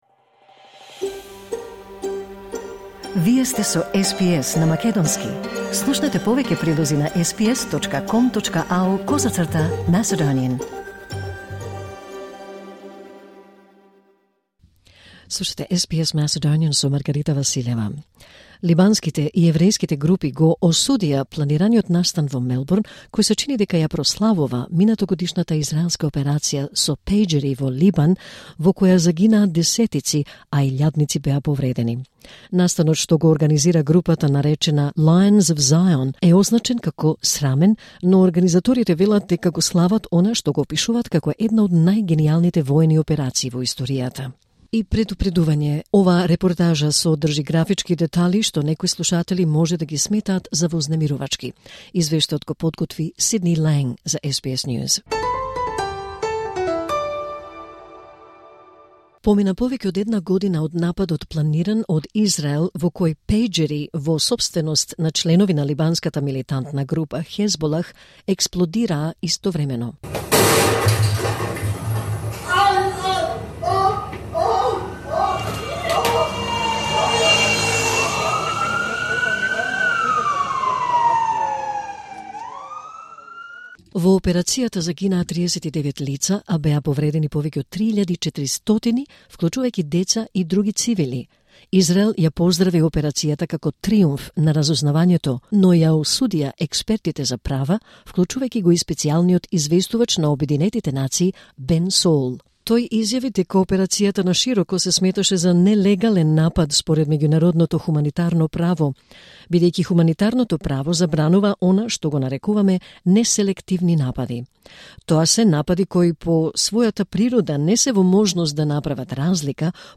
И предупредување, оваа репортажа содржи графички детали што некои слушатели може да ги сметаат за вознемирувачки.